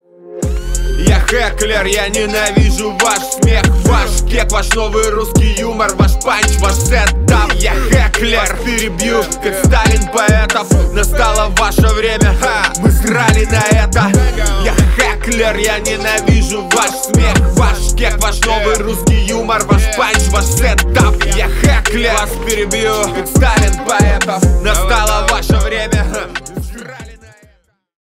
Рэп и Хип Хоп